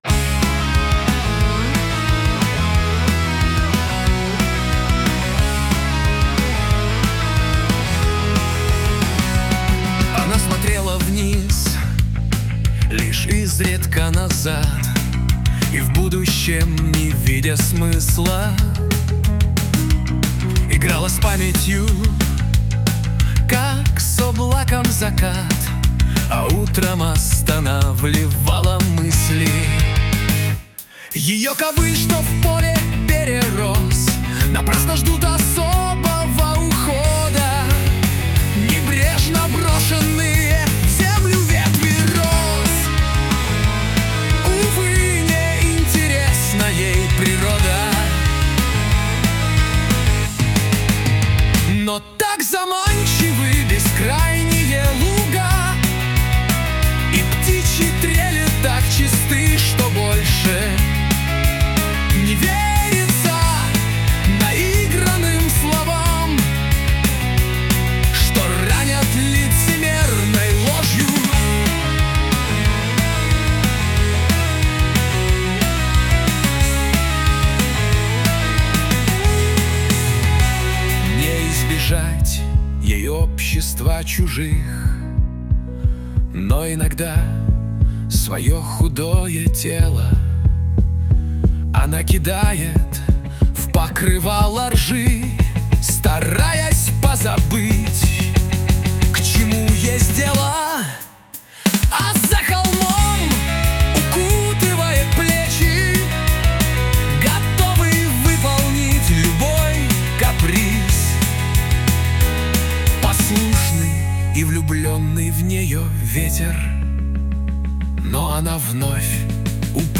Ии не может читать с правильным ударением).